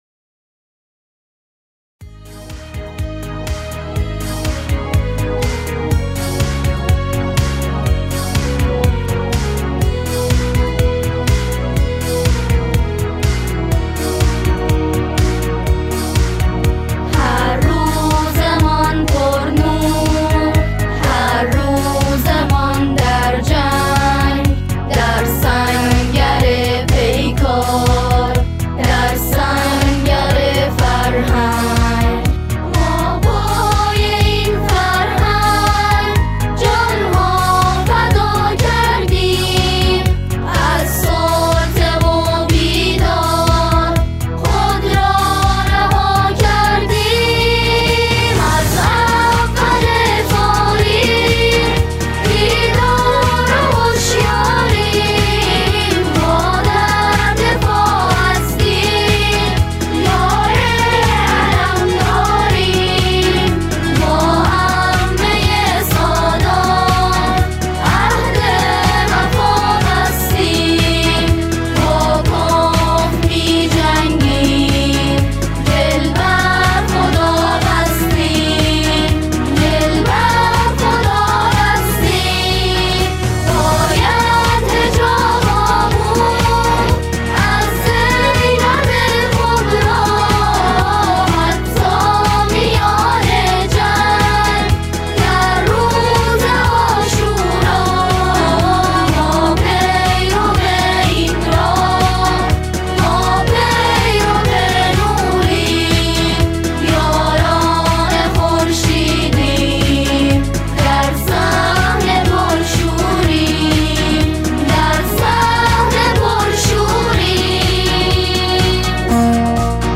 دانلود آهنگ بی کلام مدافعان حجاب